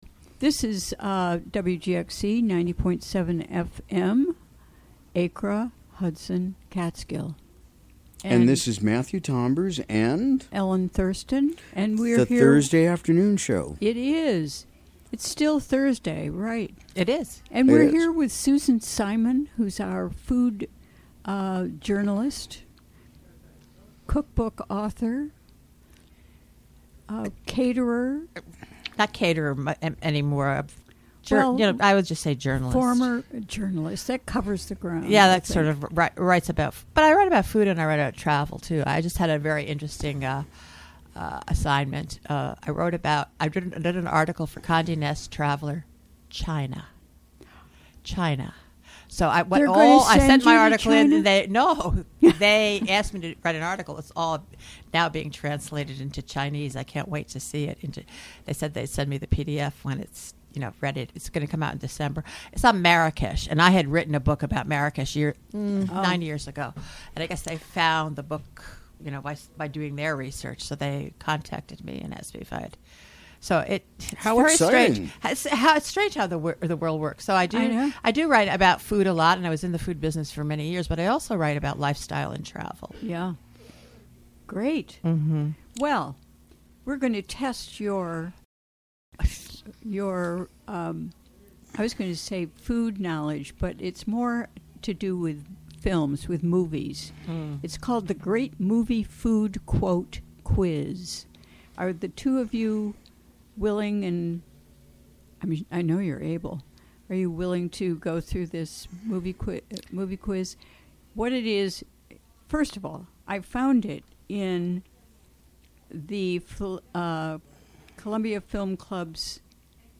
Recorded during the WGXC Afternoon Show on October 19, 2017.